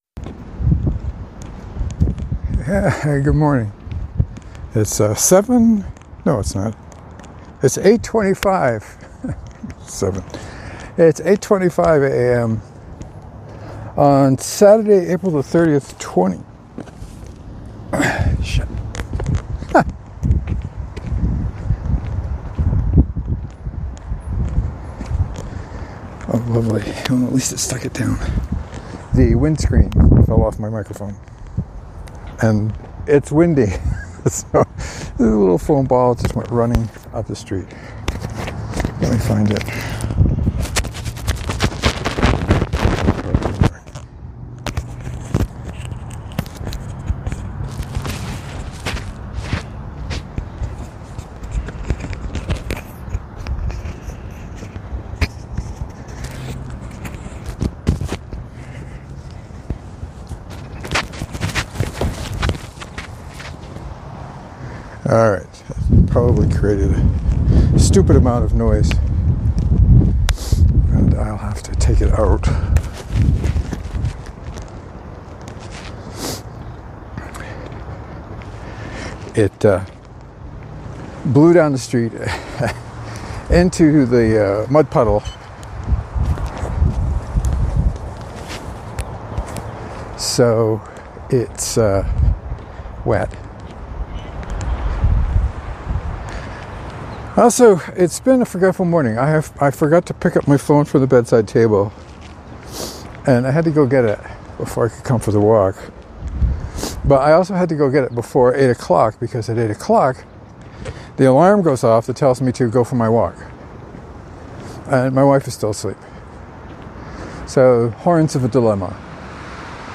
It started out when the wind pulled the foam screen off the mic and sent it blowing down the street.